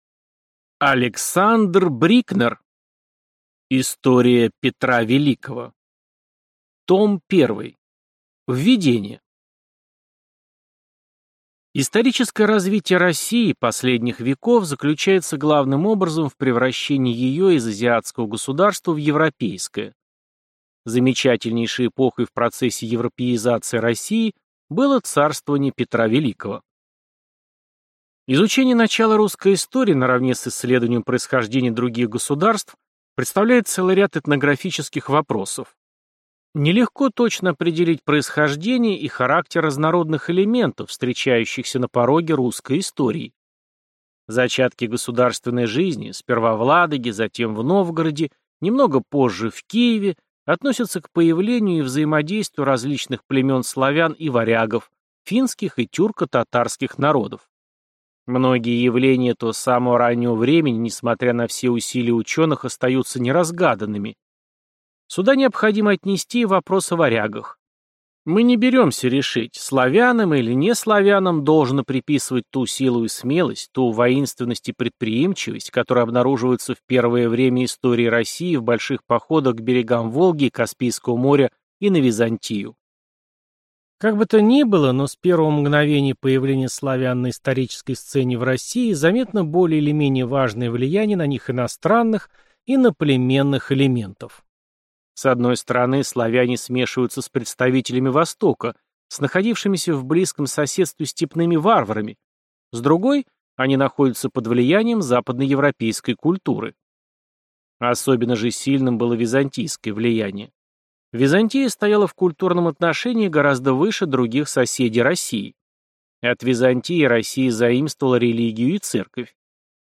Аудиокнига История Петра Великого | Библиотека аудиокниг